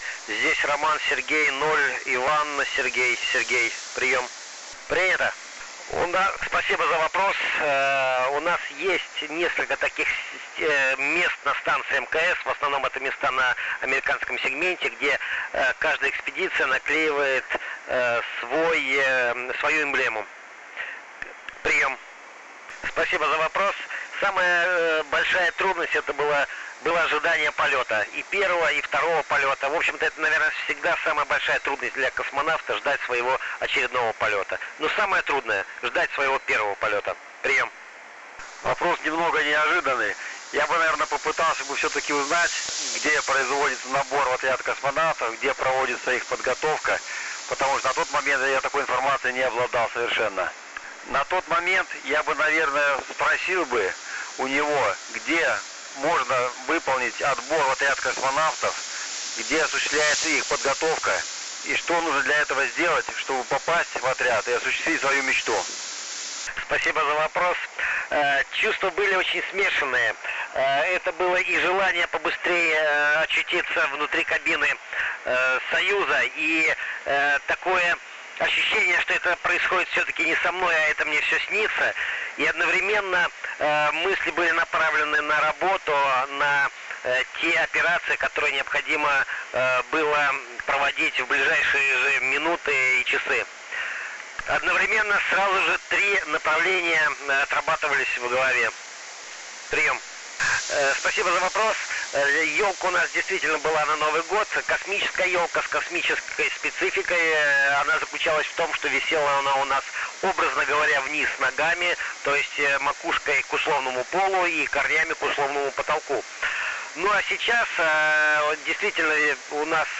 МКС на 145.800 МГц. 26.02.2017г. (09.15 UTC). Сеанс связи экипажа 50-й экспедиции МКС с Санкт-Петербургом.
Начало » Записи » Записи радиопереговоров - МКС, спутники, наземные станции
Общение российских космонавтов с радиолюбителями в Центре космической связи в торгово-развлекательном комплексе «Питер РАДУГА»